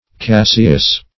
Cassius \Cas"sius\, n. [From the name of the discoverer, A.